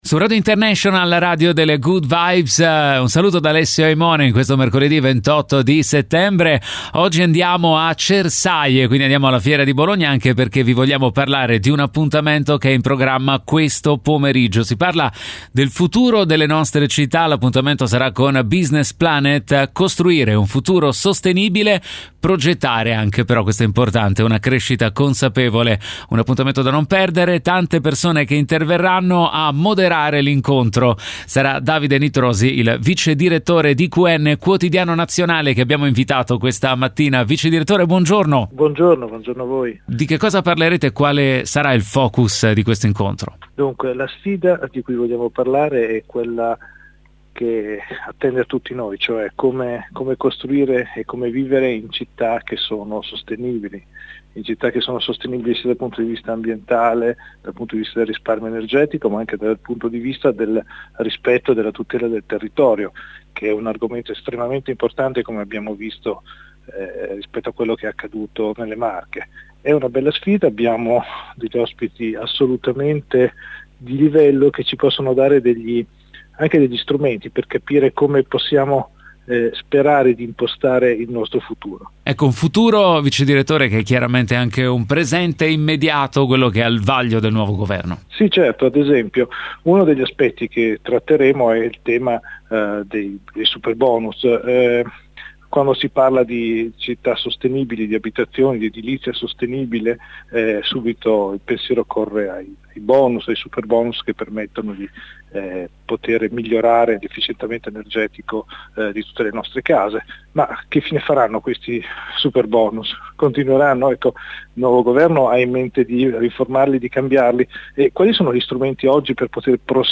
l’intervista a